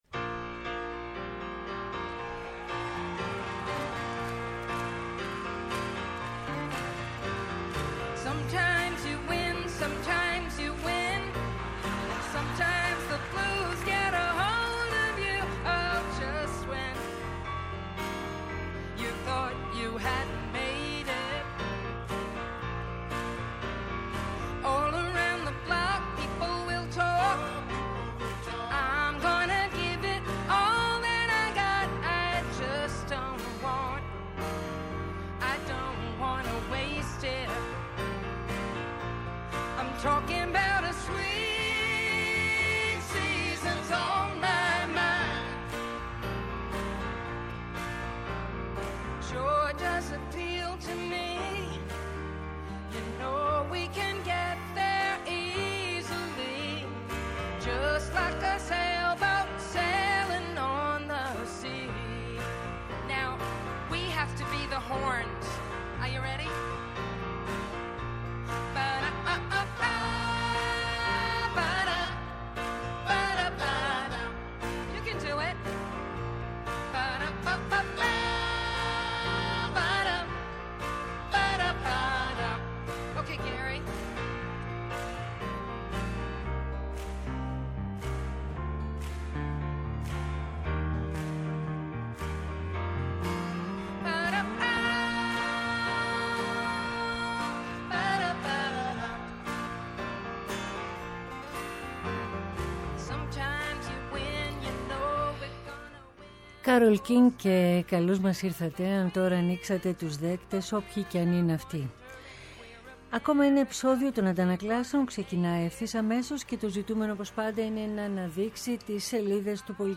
Ο απόηχος της εγχώριας και διεθνούς πολιτιστικής ειδησιογραφίας με στόχο την ενημέρωση, τη συμμετοχή, και τελικά την ακρόαση και διάδραση. ‘Ενα ραδιοφωνικό “βήμα” σε δημιουργούς που τολμούν, αναδεικνύουν την δουλειά τους, προτείνουν και αποτρέπουν.